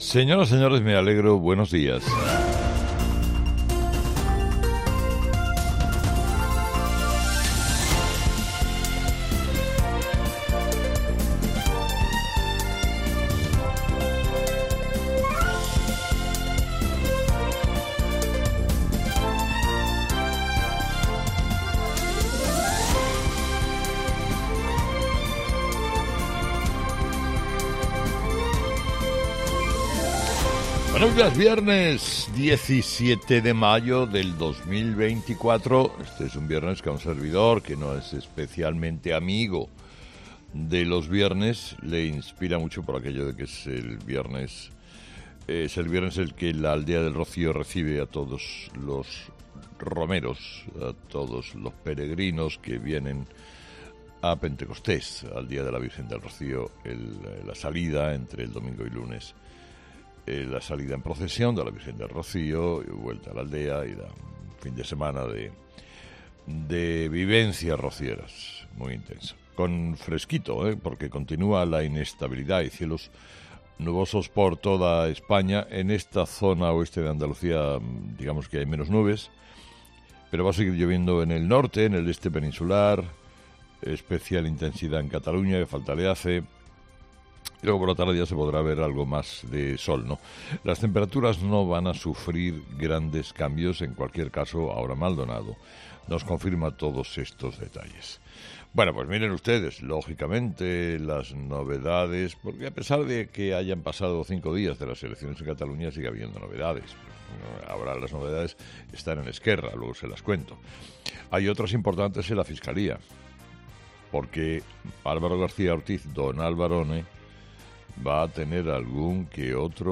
Escucha el análisis de Carlos Herrera a las 06:00 en Herrera en COPE del viernes 17 de mayo
Carlos Herrera, director y presentador de 'Herrera en COPE', comienza el programa de este viernes analizando las principales claves de la jornada que pasan, entre otras cosas, por la polémica sobre el barco con armas para Israel en España.